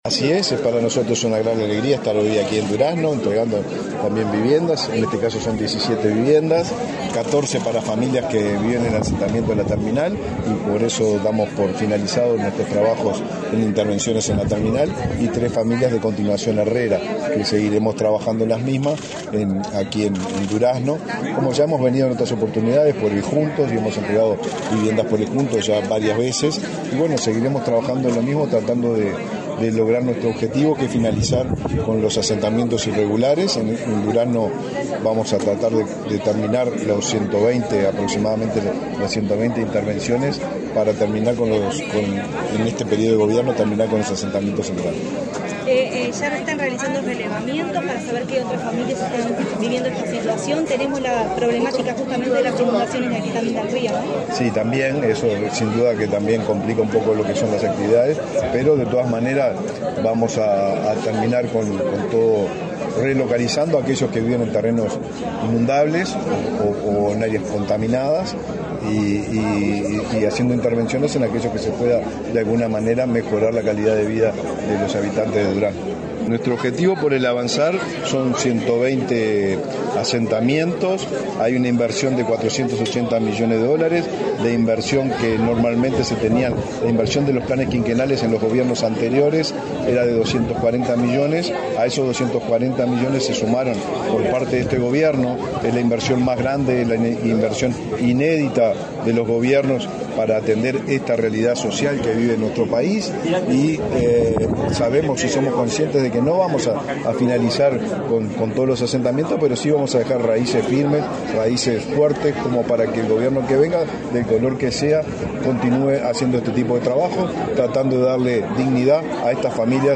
Declaraciones del ministro de Vivienda, Raúl Lozano
El ministro de Vivienda, Raúl Lozano, dialogó con la prensa en Durazno, antes de participar en el acto de entrega de 17 viviendas del plan Avanzar.